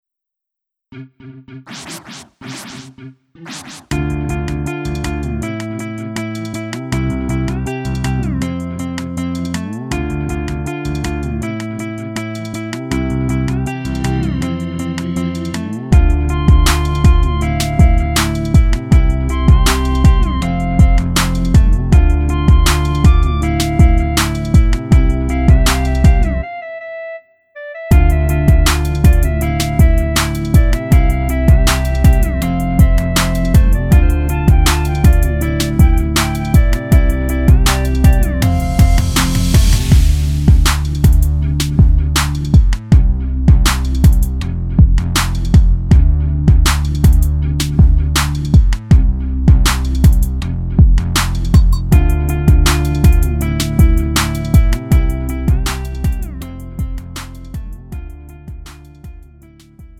음정 원키 2:56
장르 가요 구분